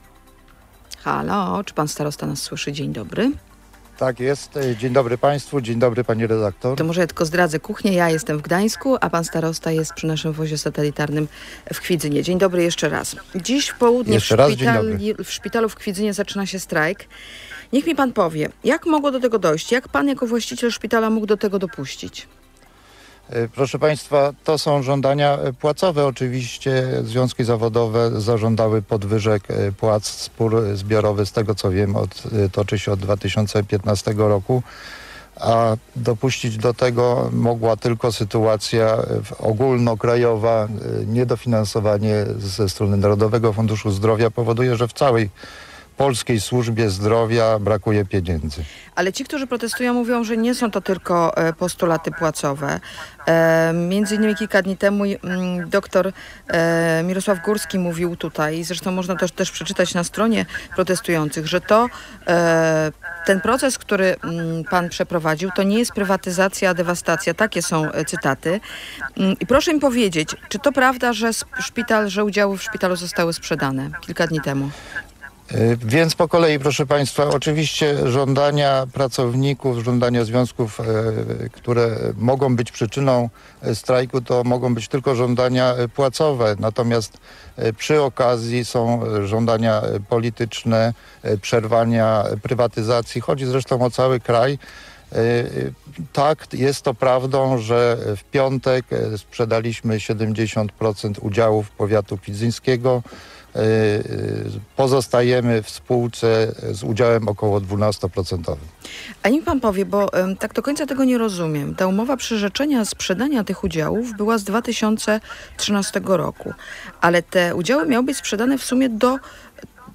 Jerzy Godzik był gościem Rozmowy Kontrolowanej.